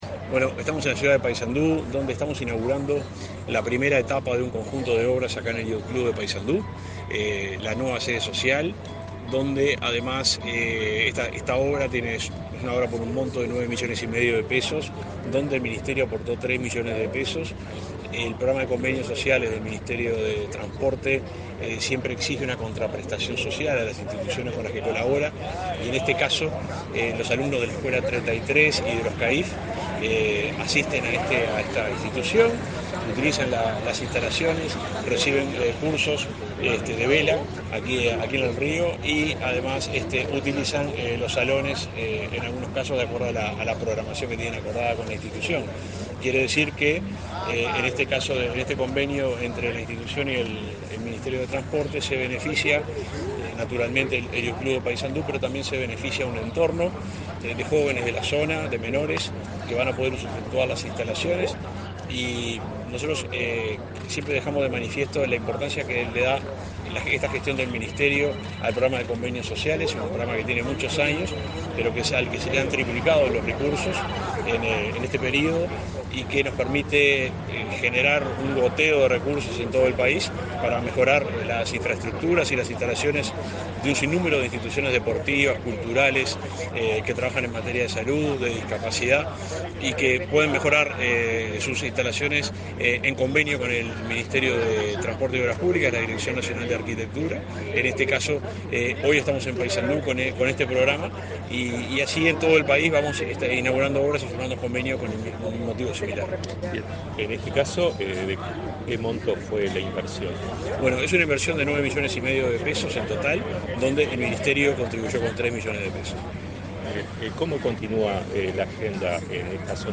Entrevista al subsecretario del MTOP, Juan José Olaizola
Este 10 de agosto, autoridades del Ministerio de Transporte y Obras Públicas (MTOP) inauguraron obras sociales en Yatch Club, en la ciudad de Paysandú
En la oportunidad, el subsecretario del MTOP, Juan José Olaizola, realizó declaraciones a Comunicación Presidencial.